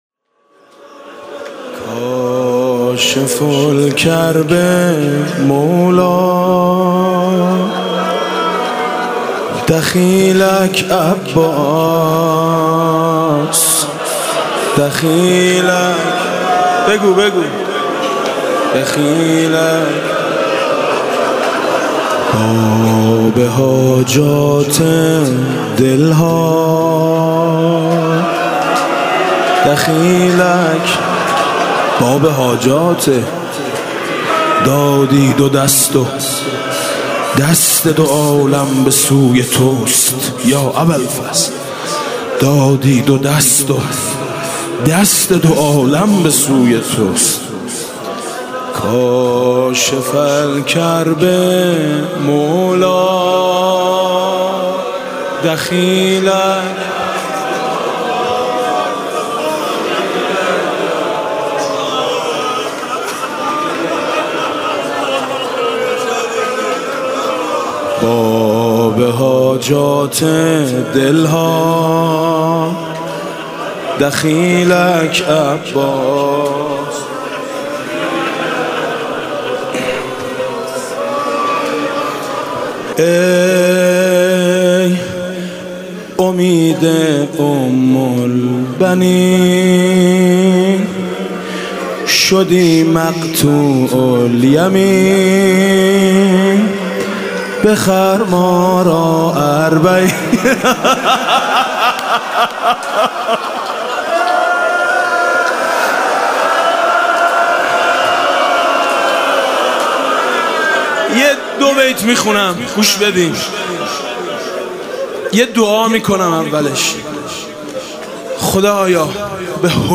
شب تاسوعا محرم 96 - هیئت میثاق - زمزمه - آبرویم خورده تیر